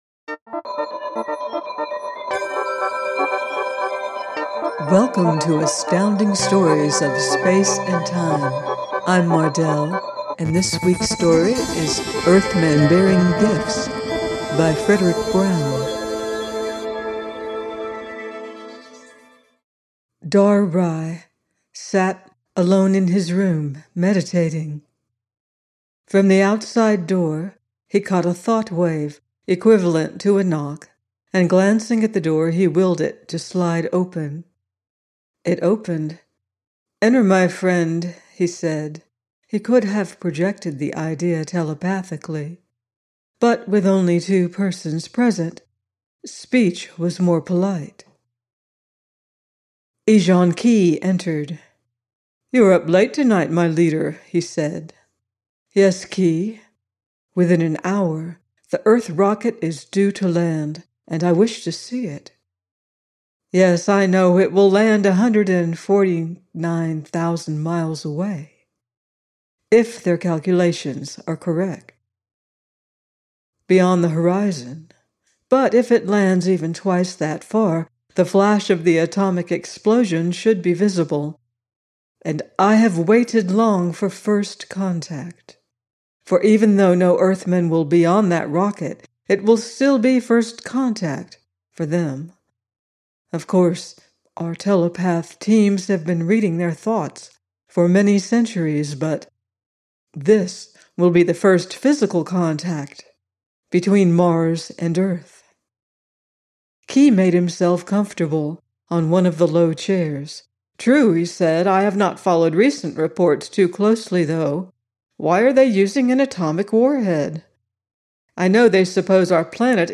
Earthmen Bearing Gifts – by Fredric Brown - audiobook